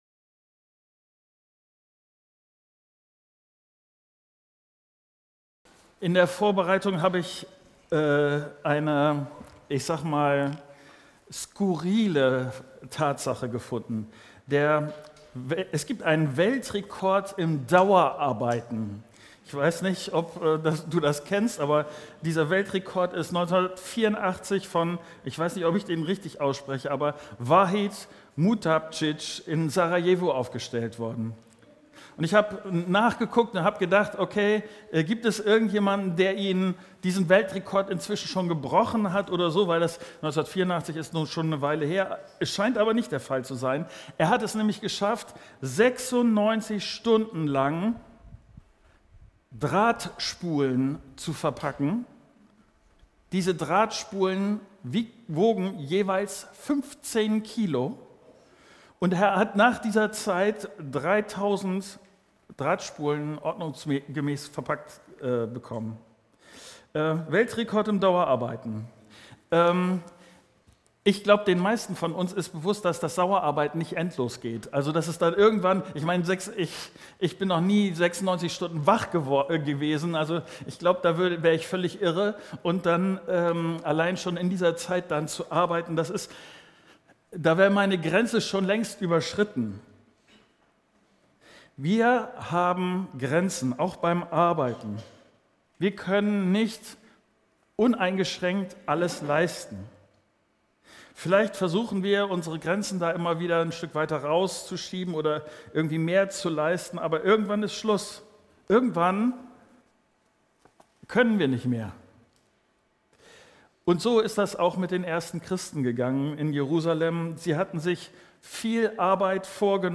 In meinem Leben stehe ich vor unterschiedlichen Herausforderungen. In dieser Predigt beschäftigen wir uns damit, wie es im Vertrauen auf Gott praktische Lösungen für Probleme geben kann.